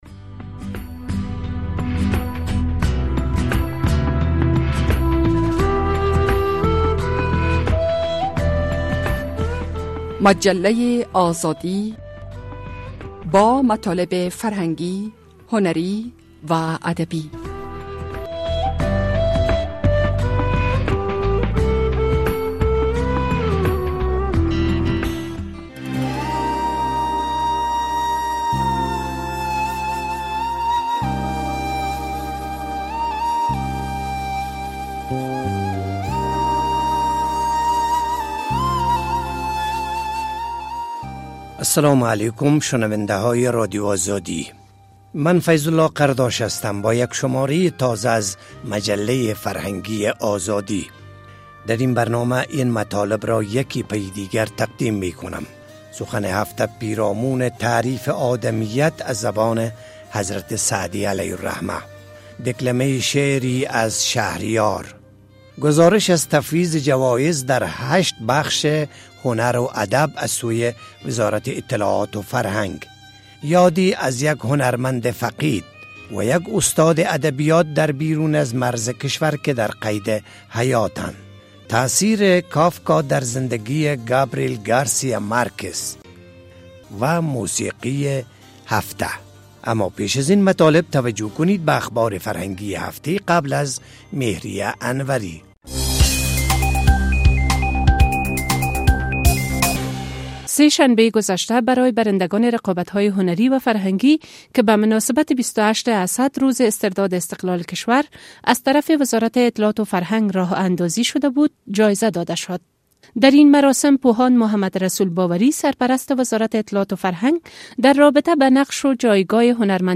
درین برنامۀ از مجله آزادی داریم: سخن هفته پیرامون تعریف آدمیت از زبان حضرت سعدی، دیکلمه شعری از شهریار ...